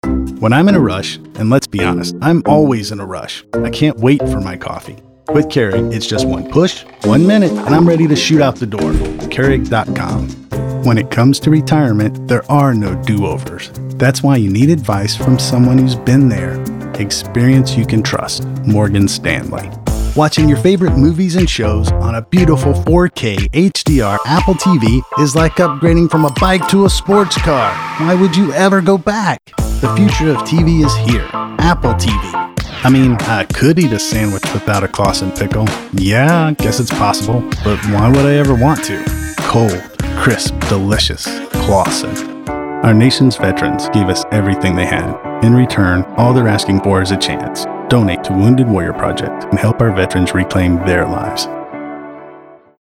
Voice Actor
Commercial Demo (mp3)